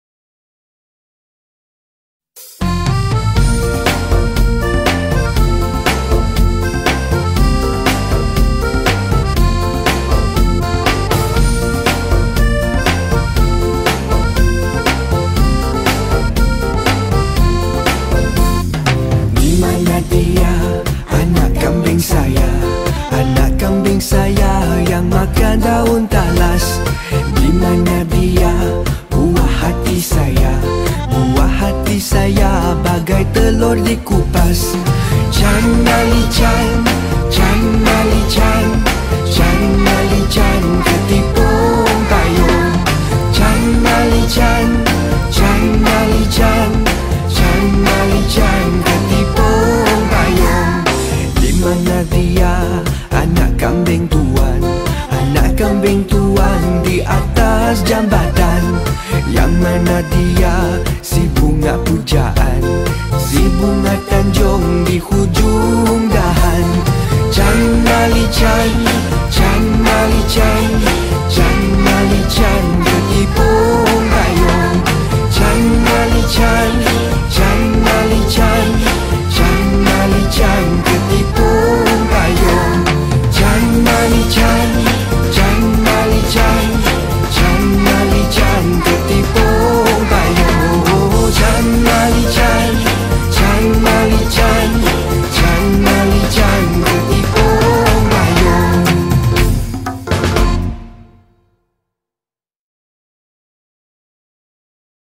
Children Song
Malay Song